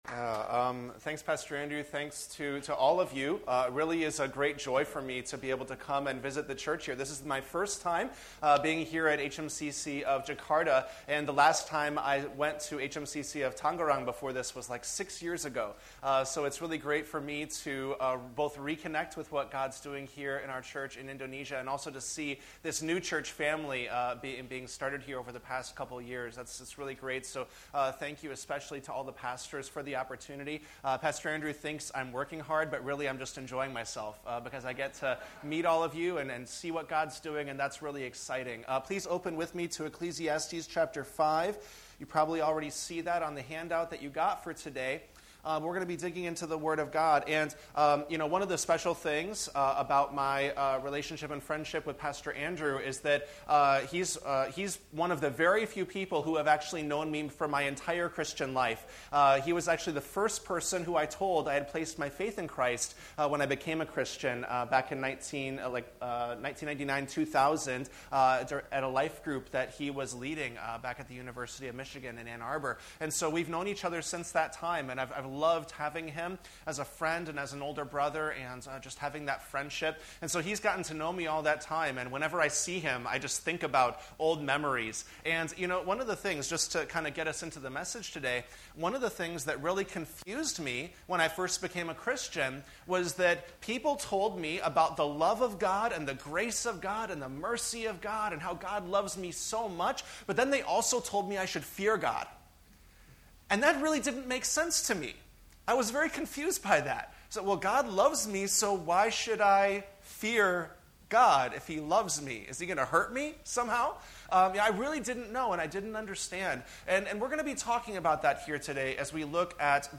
Sermon Summary